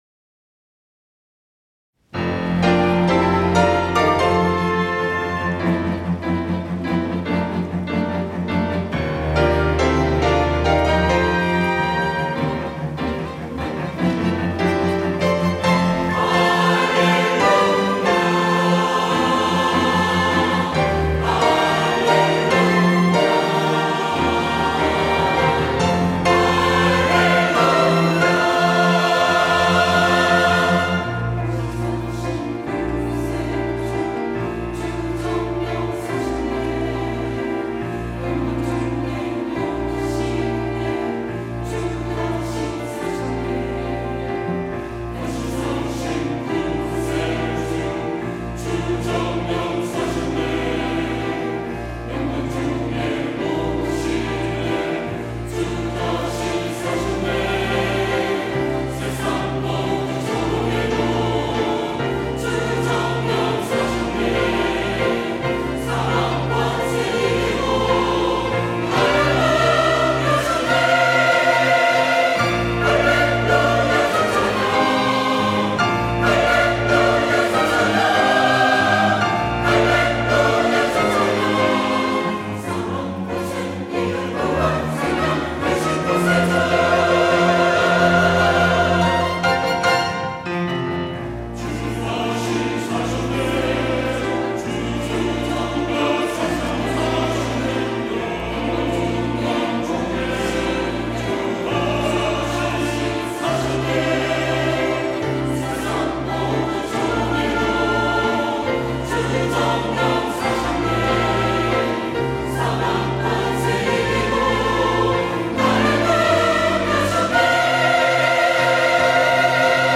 호산나(주일3부) - 다시 사신 구세주
찬양대